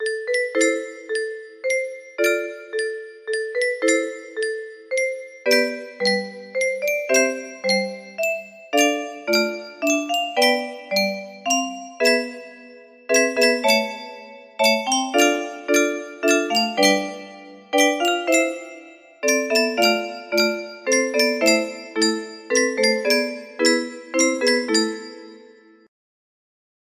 Maísól music box melody